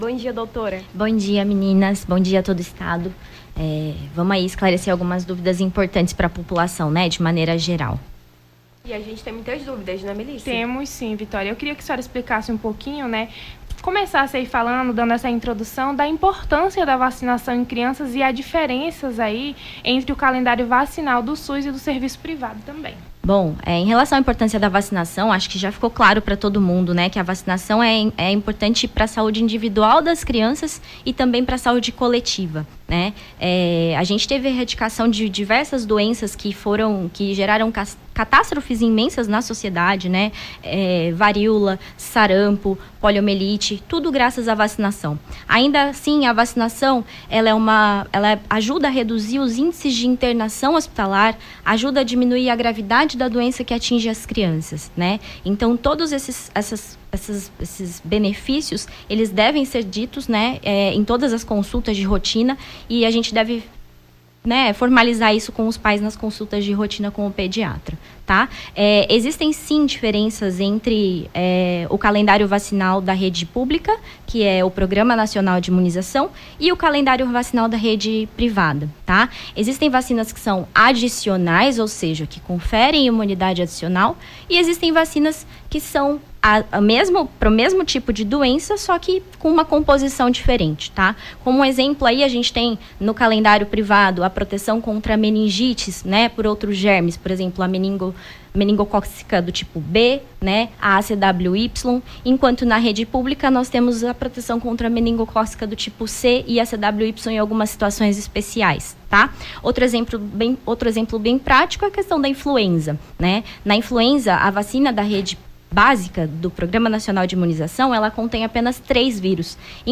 AO VIVO: Confira a Programação